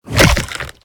flesh2.ogg